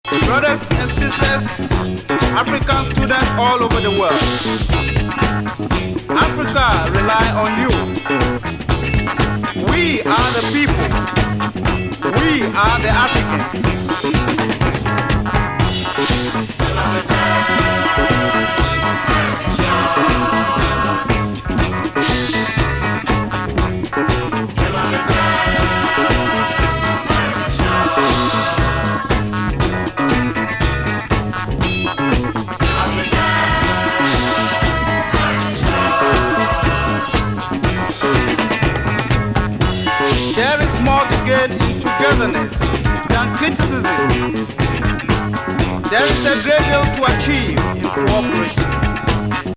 熱烈ファンキー・アフロ・グルーヴ